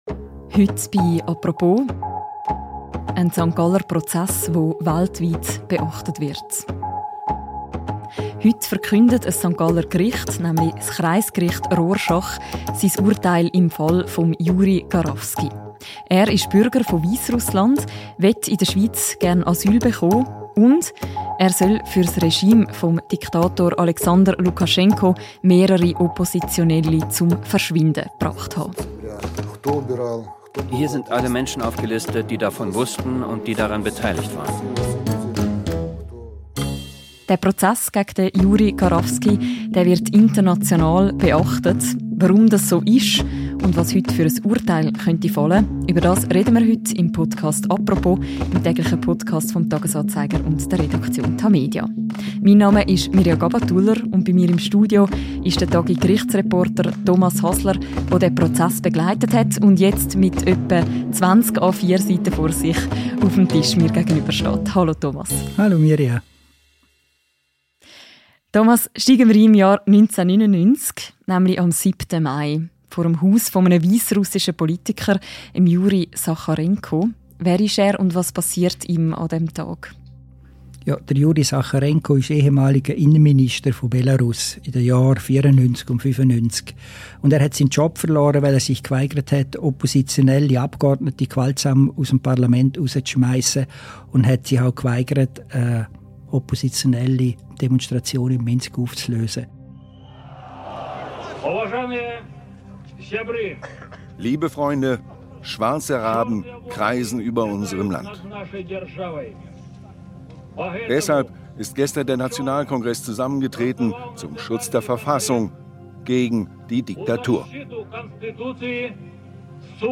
Der Podcast enthält O-Töne aus der Dokumenation «Die Morde von Minsk» (DW)